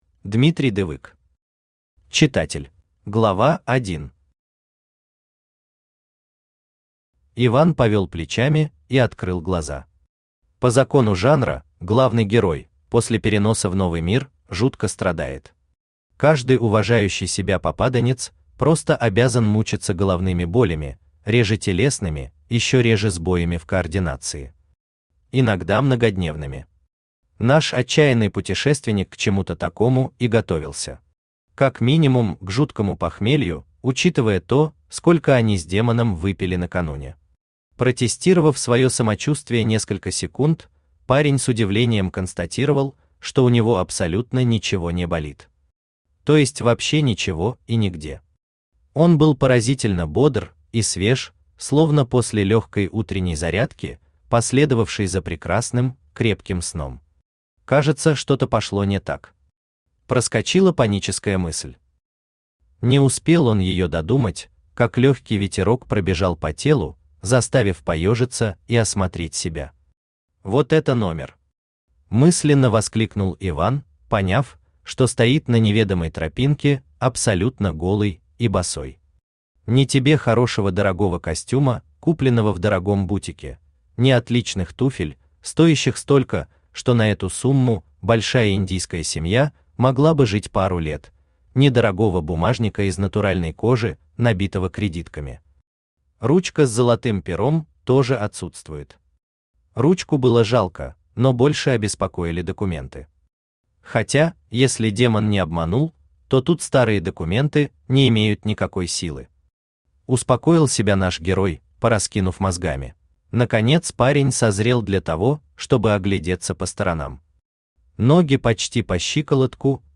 Аудиокнига Читатель | Библиотека аудиокниг
Aудиокнига Читатель Автор Дмитрий Дывык Читает аудиокнигу Авточтец ЛитРес.